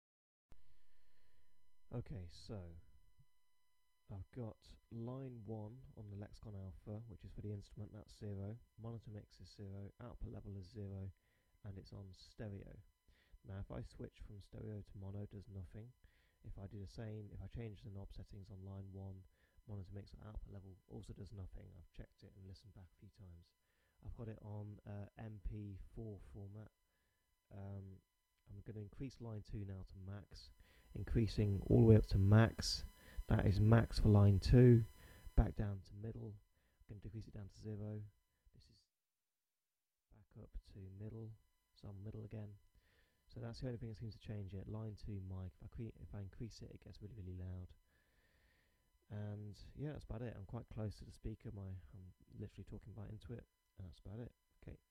High Pitched Noise
I've been trying to record myself playing the violin so that I could create a small channel on YouTube.
Does that mean YouTube edits out the whining sound?